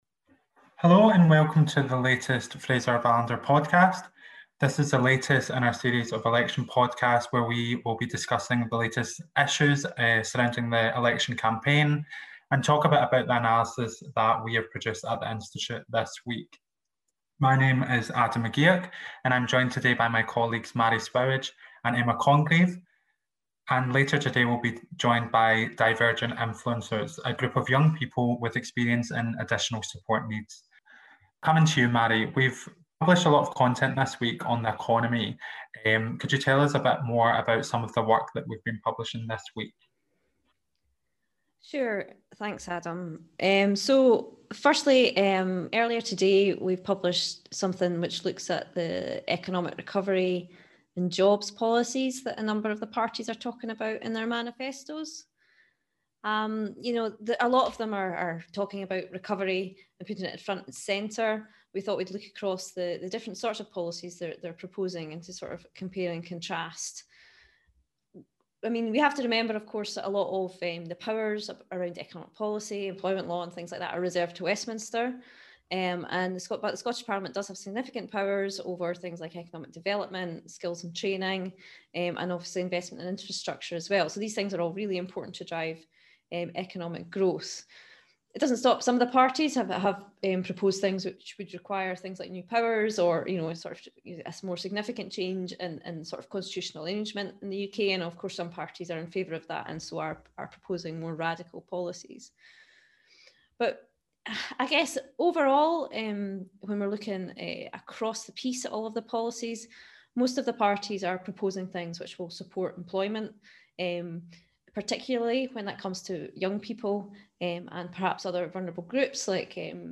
Our sixth election podcast looks at some of the key policy issues that have arisen in the campaign this week – with a brief discussion on key points within the manifestos, including learning disabilities, child poverty, and some insights into what to look out for in the coming week. We were also joined by Divergent Influencers, who work with the Association for Real Change (ARC Scotland) to promote the improvement of transitions to adulthood for young people with additional support needs (ASN).